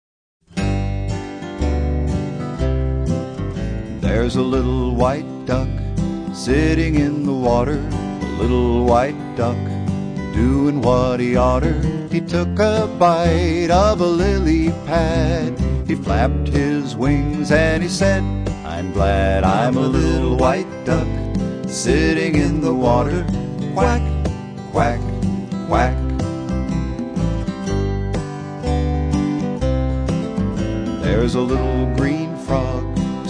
all performed in an irresistable bluegrass style.